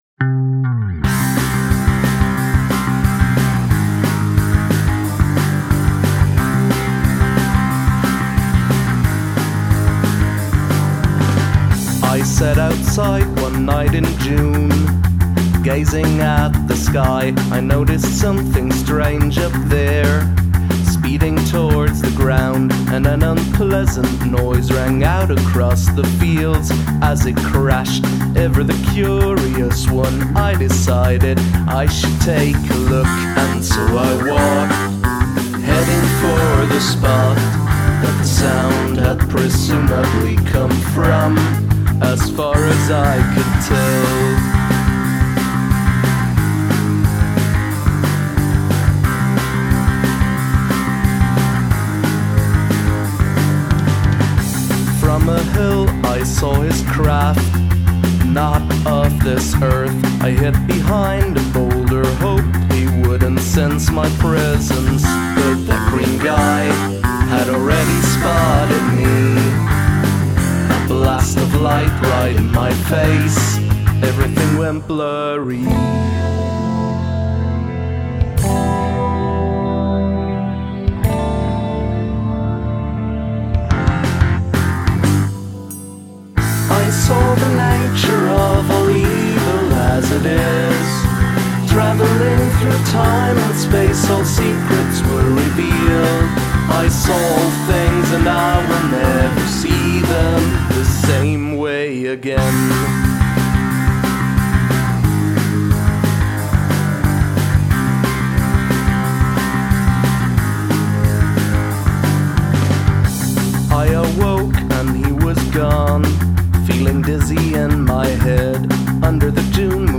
SpoilerLike most space rock songs, it's basically a drug trip.
the fuzz guitar drives the whole song and I don't particularly like the tone of the fuzz, which might just be me.
It feels a little underwhelming in the face of the power chord and palm mute chug riffs.
Drums could use a bit more oomph.
Fun, poppy, upbeat, very listenable.
I prefer your classic songwriter inspired stuff to this more pop-punk style but this is still a well crafted song.